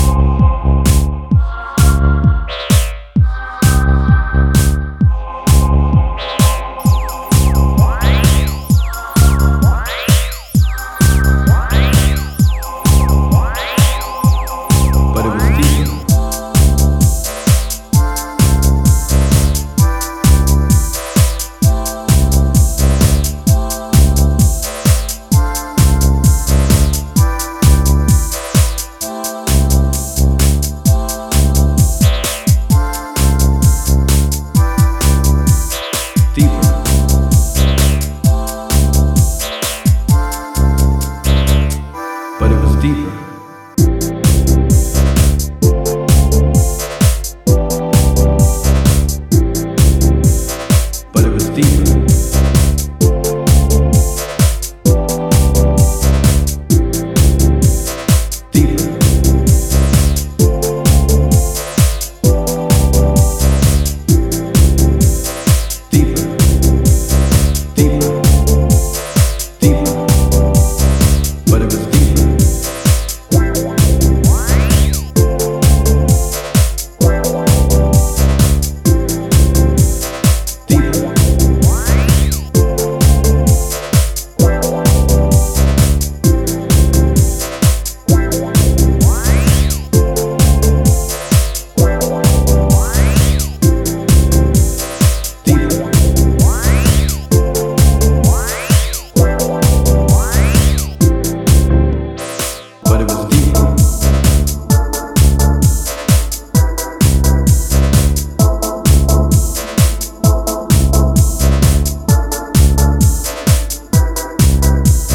is built around a funky guitar riff, a groovy bass
an afterparty banger full of crazy vocals